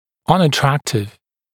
[ˌʌnə’træktɪv][ˌанэ’трэктив]непривлекательный